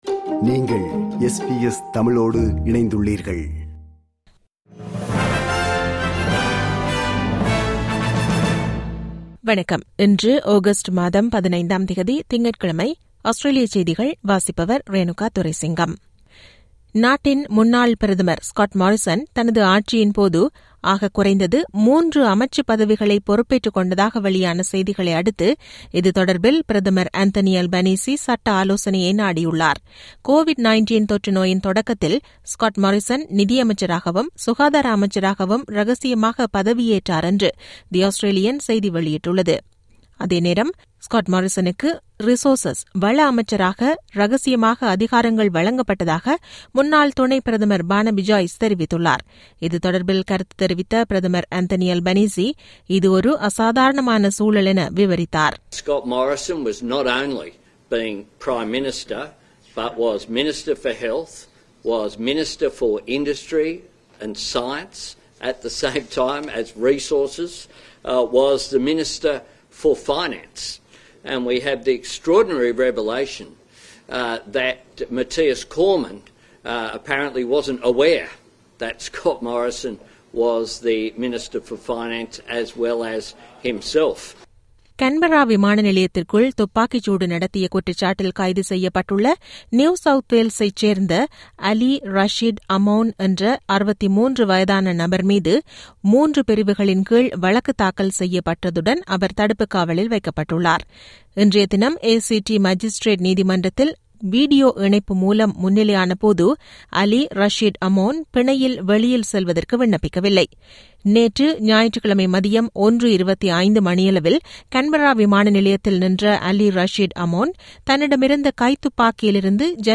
Australian news bulletin for Monday 15 Aug 2022.